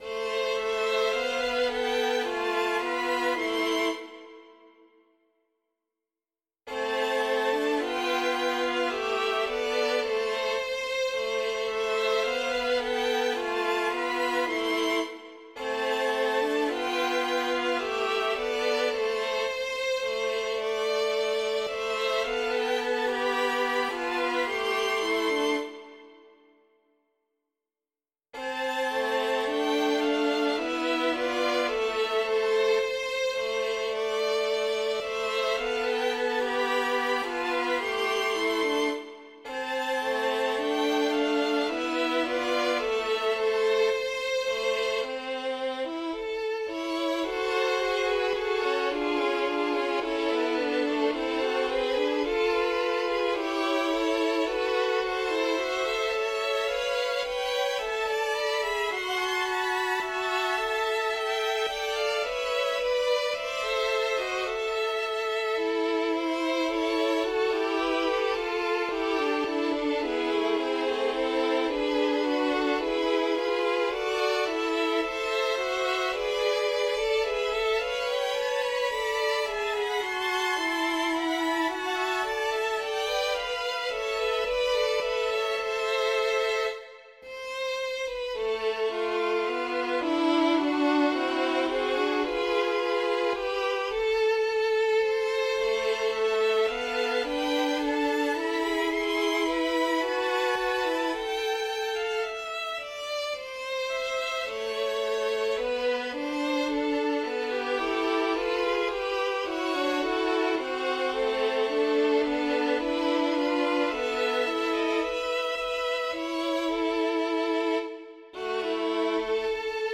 mp3-Aufnahme: mit midi Instrument